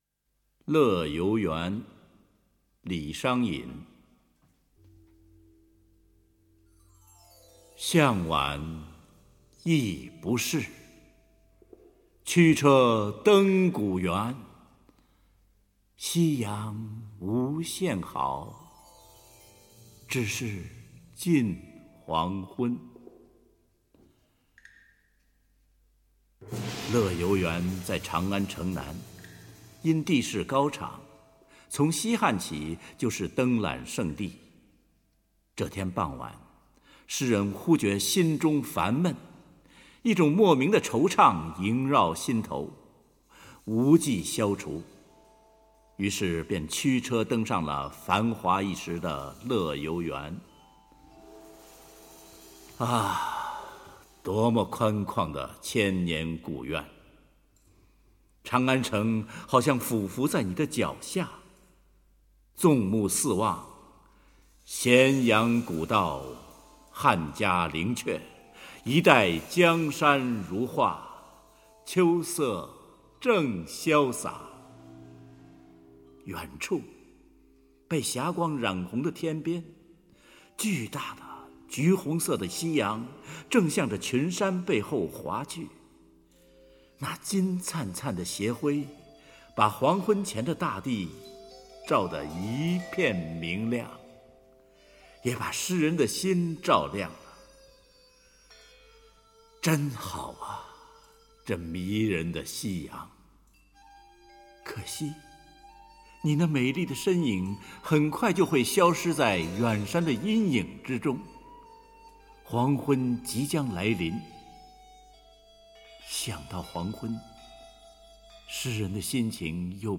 融文学 朗诵 音乐于一炉的艺术精品
他的声音的确很迷人！